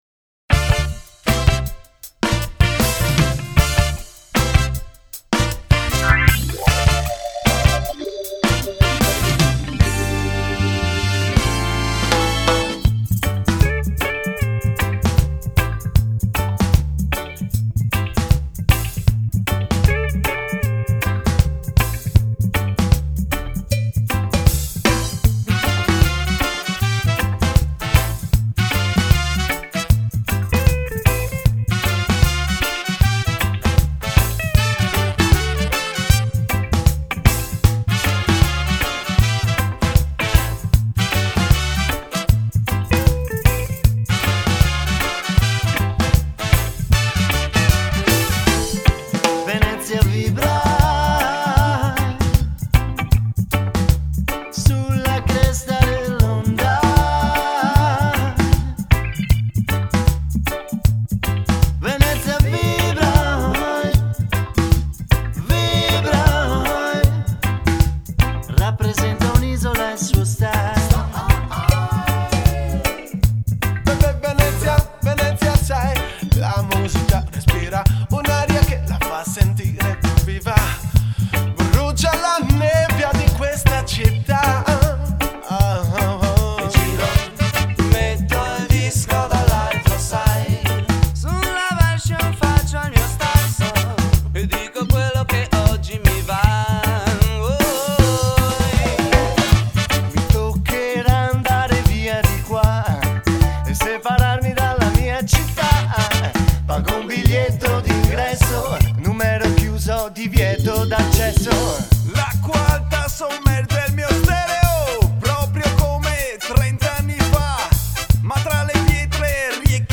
chitarra e voce
sequencer e voce
batteria